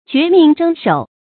决命争首 jué mìng zhēng shǒu
决命争首发音
成语注音 ㄐㄩㄝˊ ㄇㄧㄥˋ ㄓㄥ ㄕㄡˇ